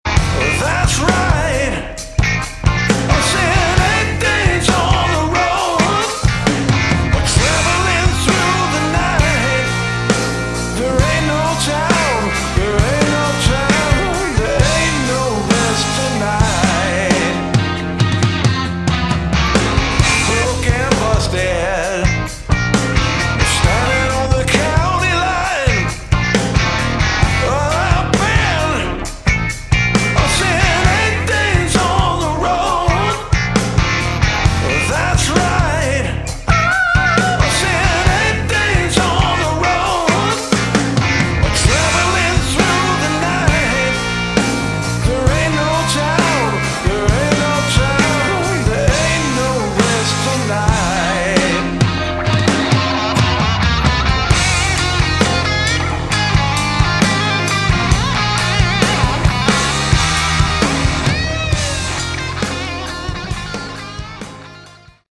Category: Melodic Rock
bass
vocals
drums
guitars